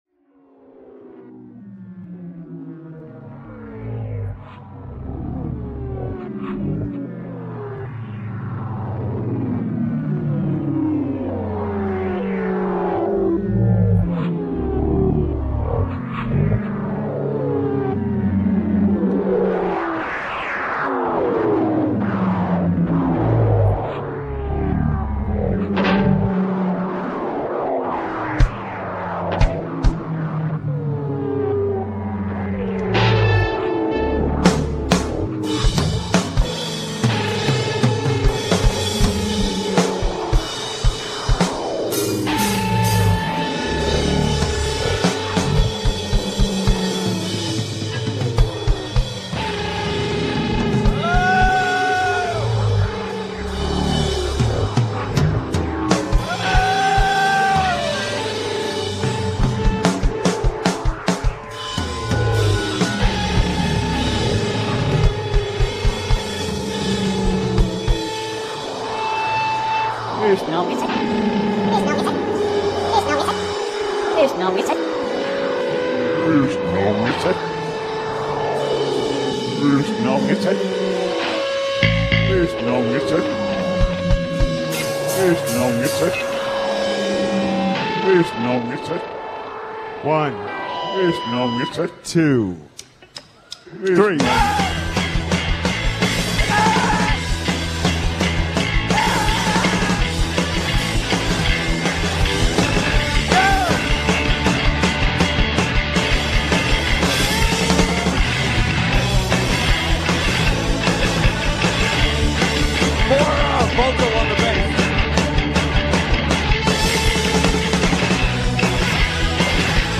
recorded at Primavera Sound
American post-punk band
guitar
bass
drums